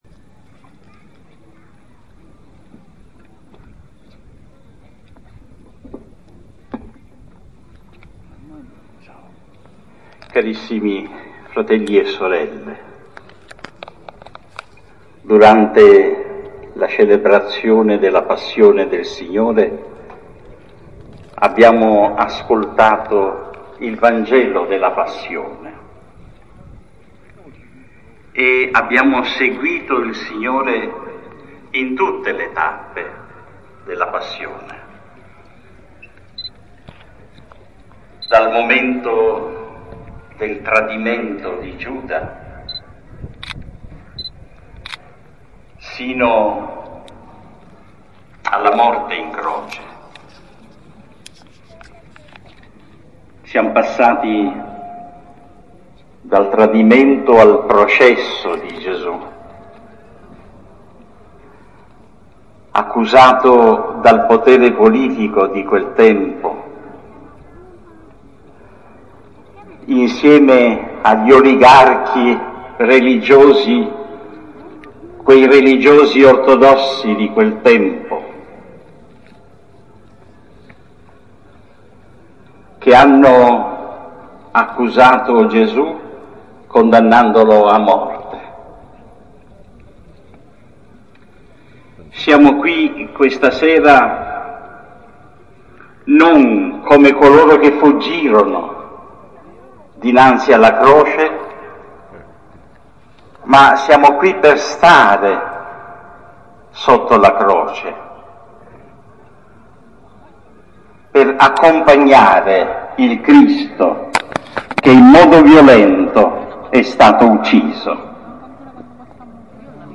In Piazza Duomo il Vescovo Mons. Giuseppe Marciante, ha rivolto ai fedeli un messaggio ed un invito alla preghiera ed al digiuno per la pace nel mondo.
AUDIO: OMELIA DEL VESCOVO AUDIO: OMELIA DEL VESCOVO